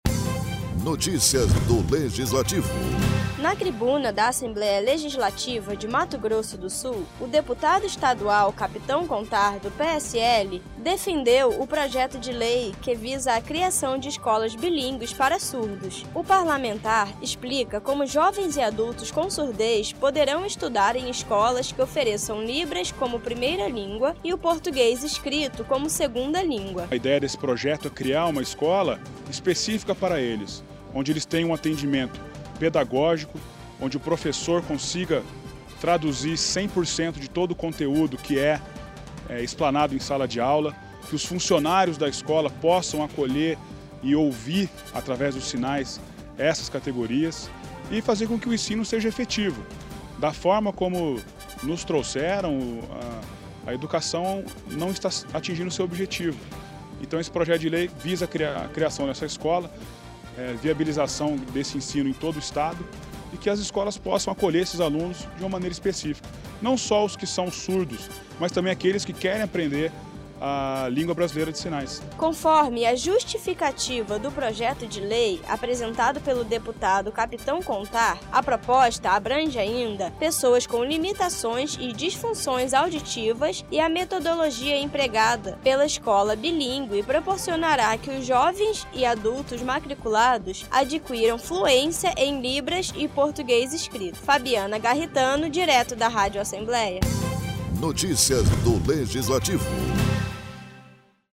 O deputadol Capitão Contar, do PSL defendeu na tribuna da Assembleia Legislativa o projeto de lei, que visa estabelecer a criação de escolas bilíngues para surdos no Estado. Jovens e adultos com surdez poderão estudar em escolas que ofereçam Libras como primeira língua e, o português escrito, como segunda língua.